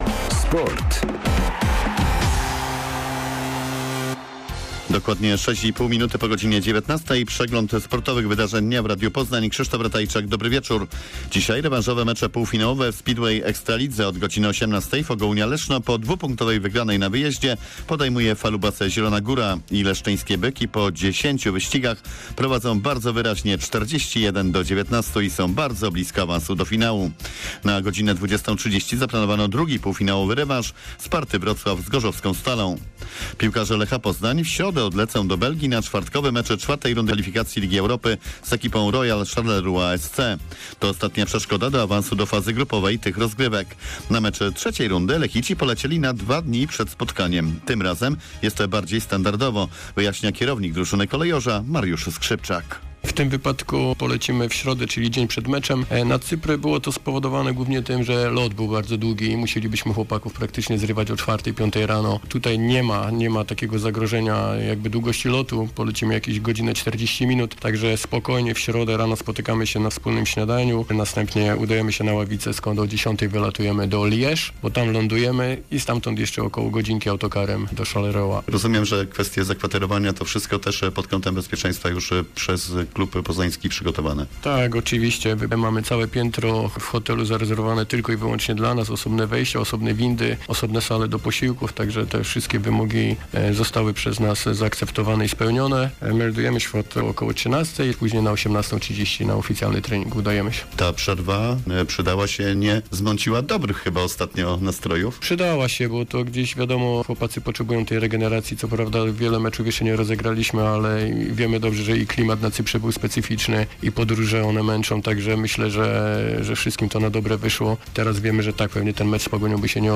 28.09. SERWIS SPORTOWY GODZ. 19:05